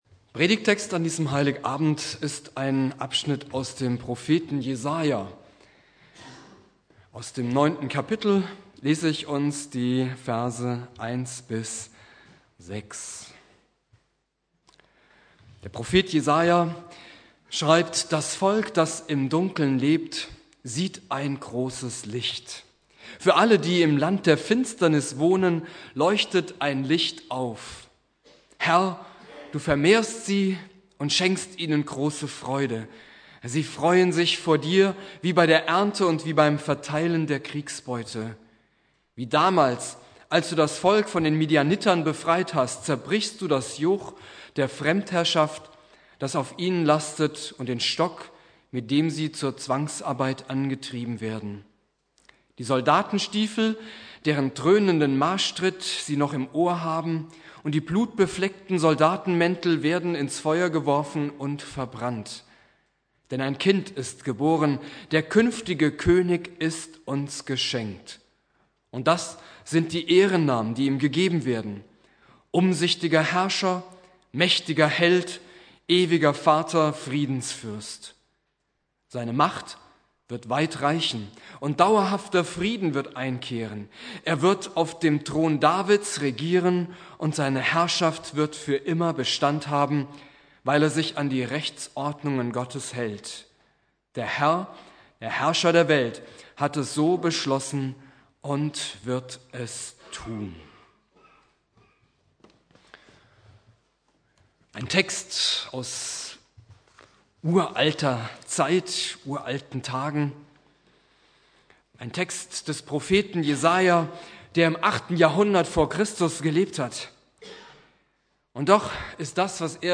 Predigt
Heiligabend Prediger